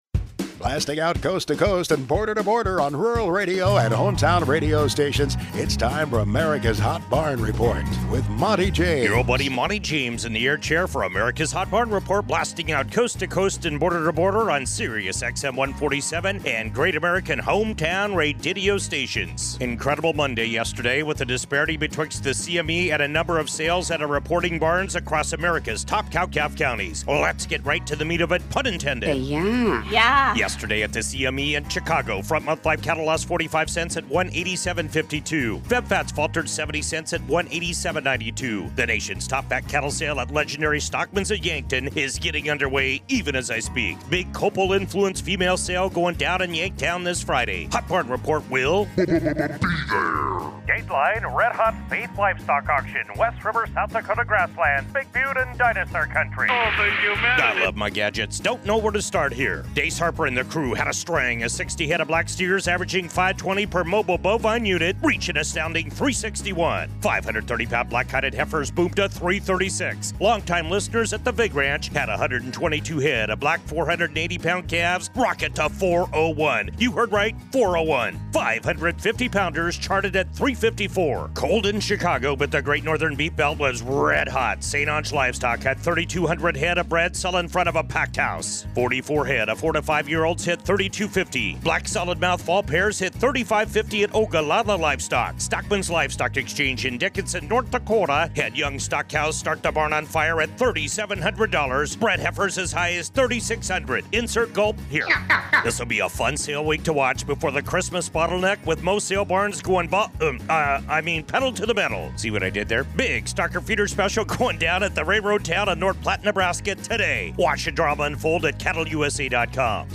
The Hot Barn Report features interviews with industry leaders, market analysts, producers and ranchers and features True Price Discovery from salebarns in Great Northern Beef Belt and across the nation.